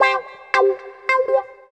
137 GTR 10-L.wav